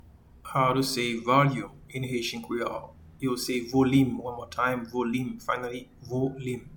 Pronunciation and Transcript:
Volume-in-Haitian-Creole-Volim.mp3